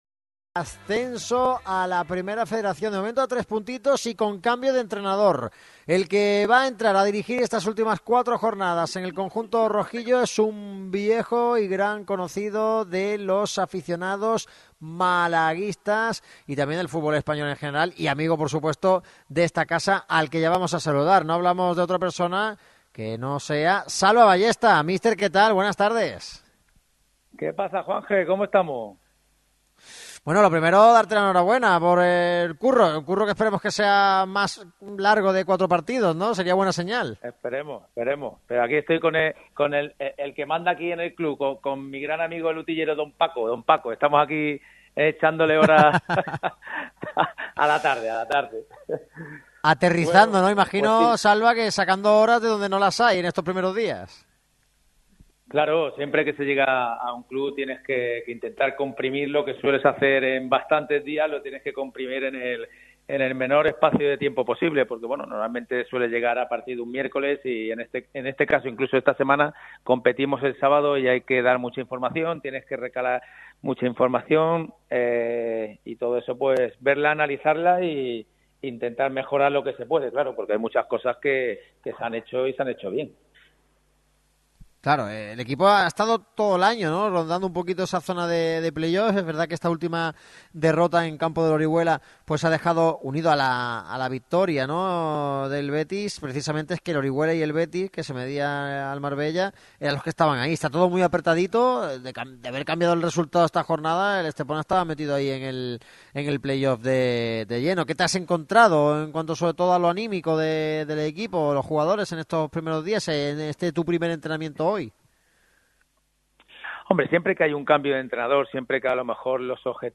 Antes de ser presentado, ya ha pasado por el micrófono rojo de Radio MARCA Málaga .